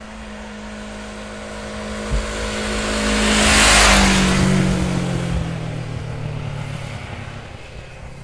moto » Motorcycle engine stop
描述：Stopping the engine in my Yamaha mt03 single cylinder.
标签： motorcycle tascam stop fieldrecording stereo yamahamt03 engine
声道立体声